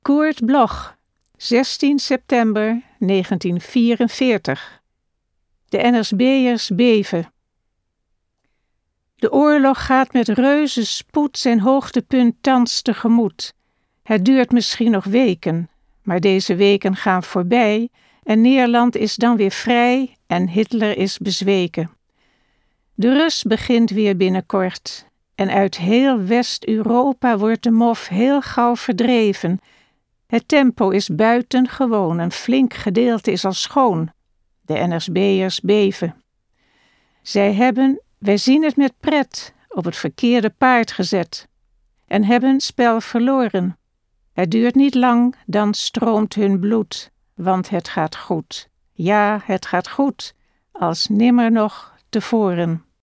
Recording: Karakter sound, Amsterdam